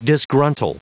Prononciation du mot disgruntle en anglais (fichier audio)
Prononciation du mot : disgruntle